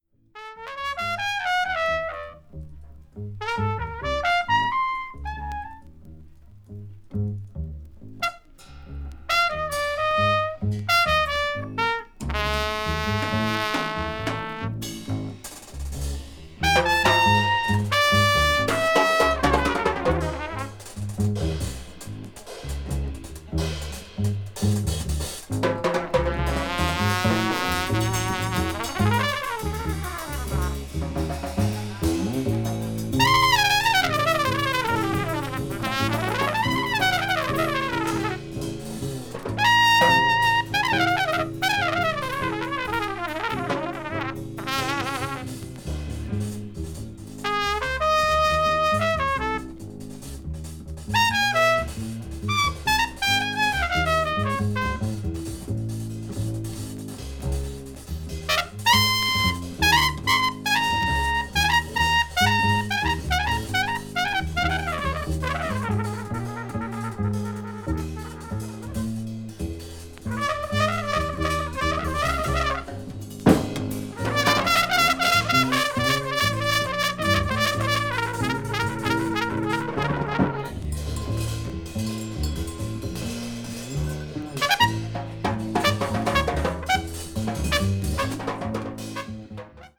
saxophonist
avant-jazz   free improvisation   free jazz